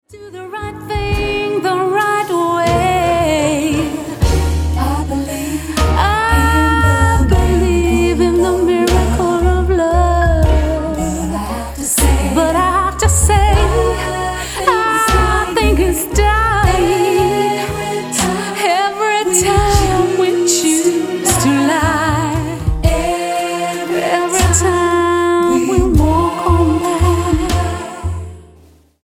Pop, jazz and dance diva
Style: Pop Approach: Mainstream